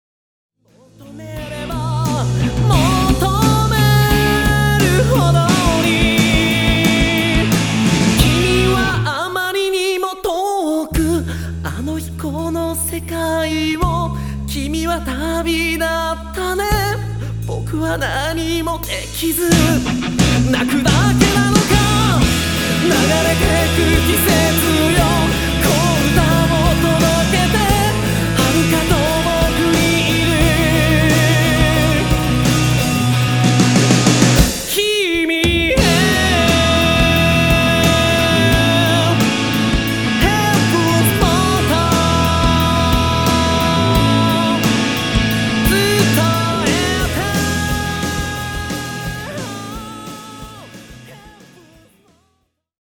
ON GUITAR
ON VOCAL
ON DRUMS！！
この音源はWEB用に圧縮してます。。